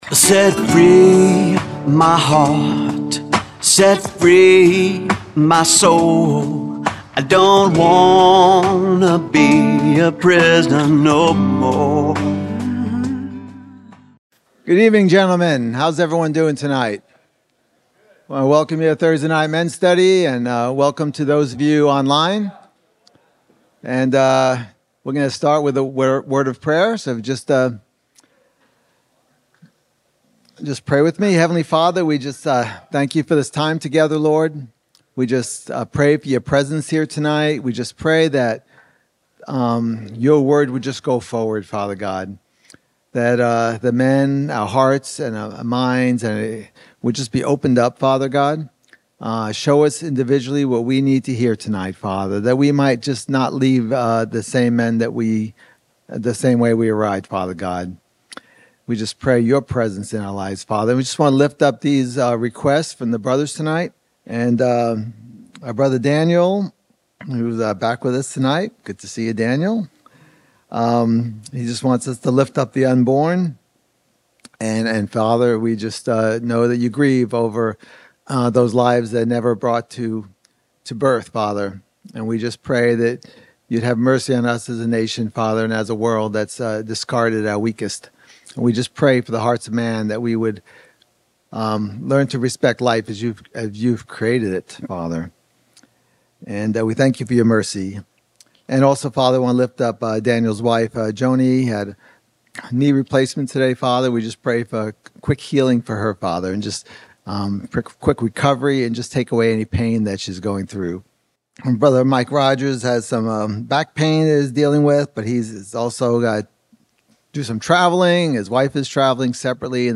Audio-only Sermon Archive
Hebrews 13 Service Type: Sunday Night Study